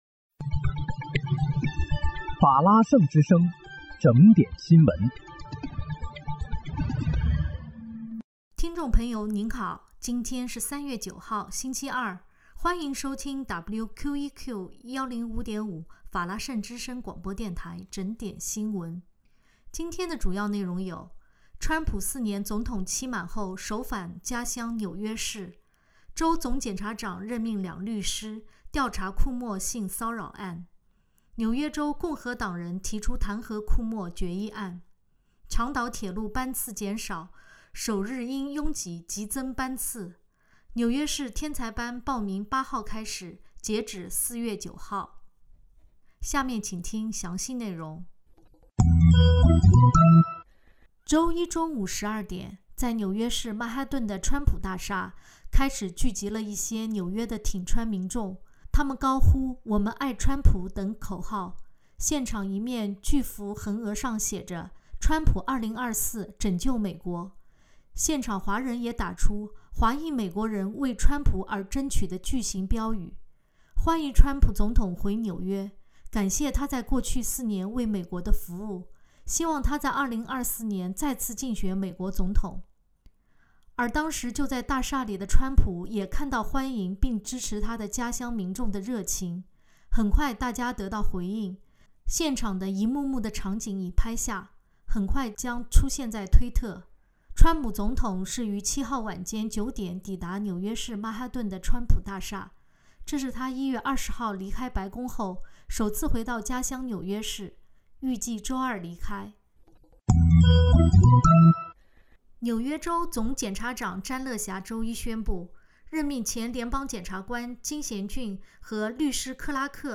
3月9日（星期二）纽约整点新闻